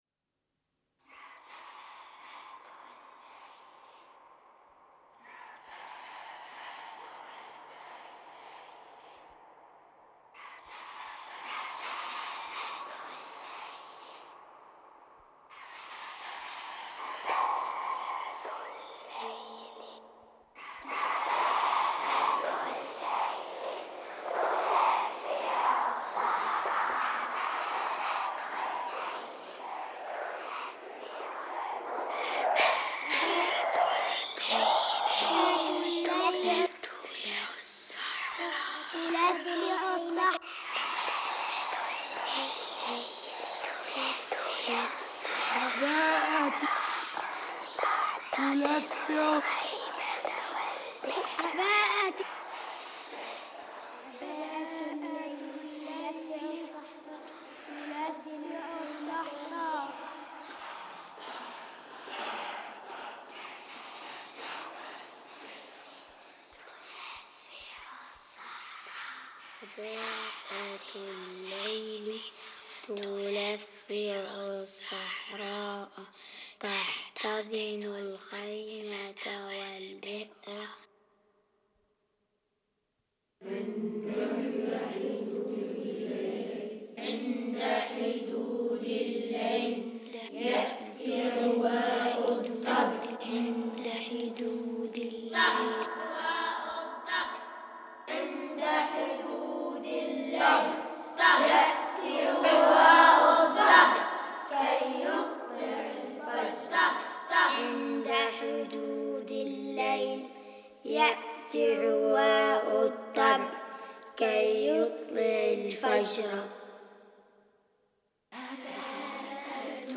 21 Magnetic tape -
Collage of voices of Bedouin children